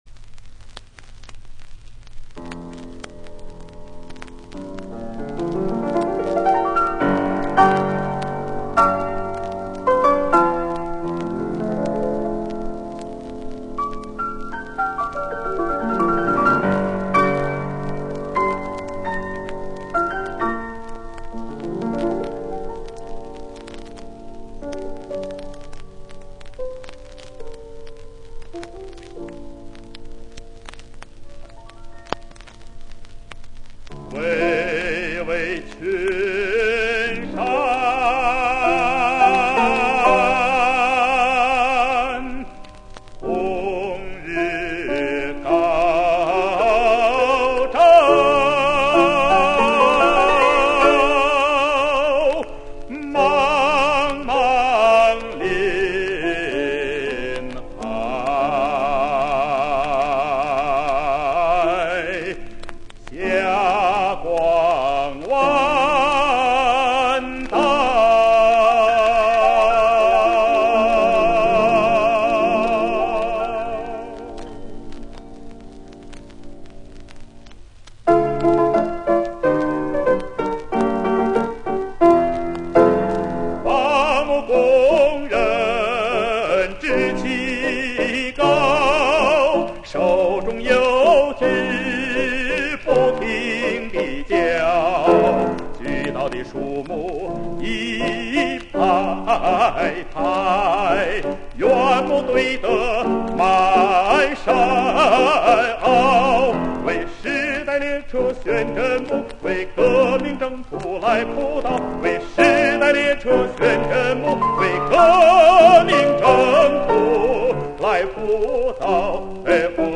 钢琴伴奏